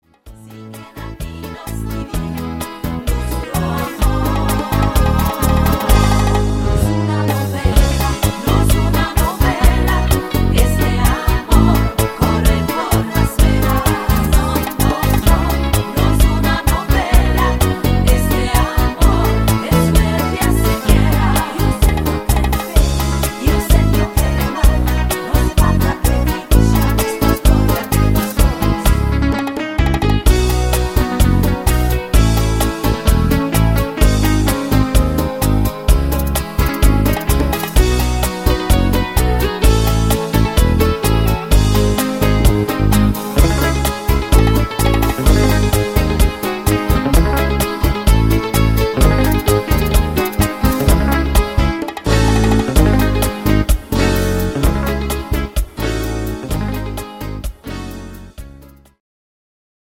echter Bachata